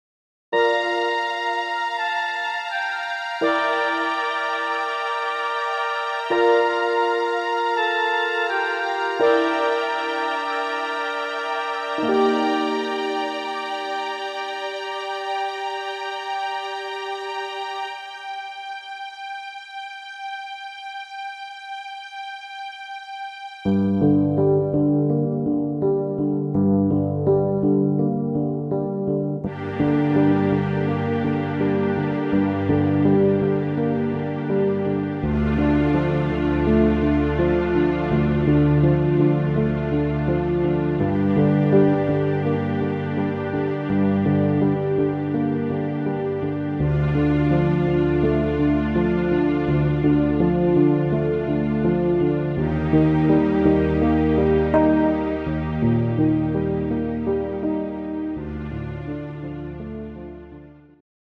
Rhythmus  Ballade
Art  Deutsch, Musical